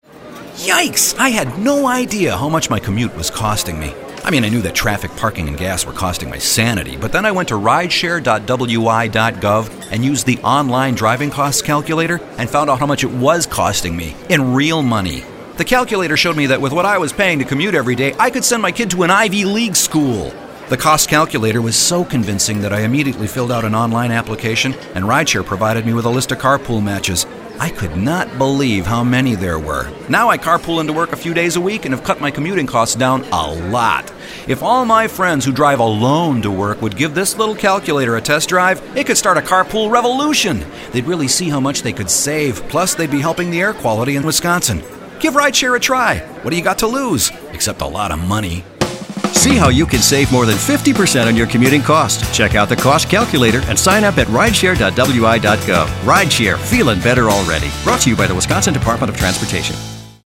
Radio ad - Cost calculator - Using the cost calculator results in registering with RIDESHARE, getting matches, and saving commute dollars.